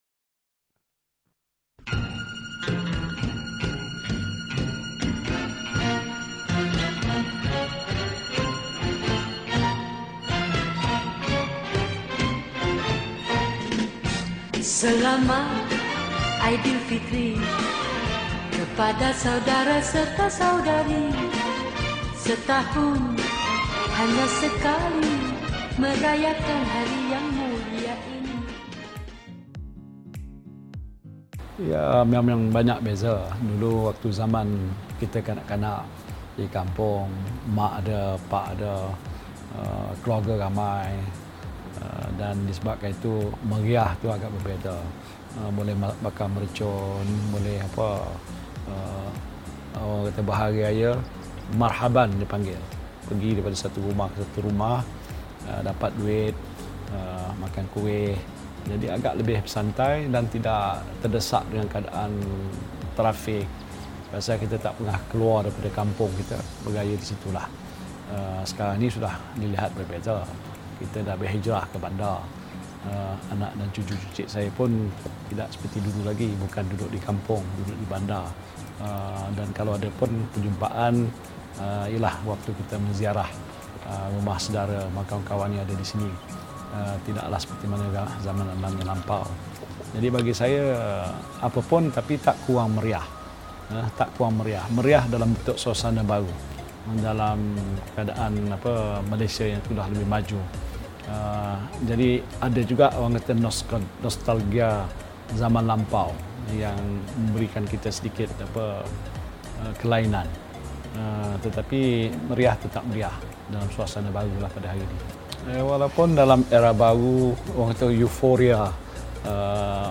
Tan Sri Muhyiddin Yassin yang juga Menteri Dalam Negeri berkongsi kemeriahan beraya zaman kanak-kanak beliau dan bagaimana semuanya berubah seiring kedewasaan dan penghijrahan beliau ke bandar.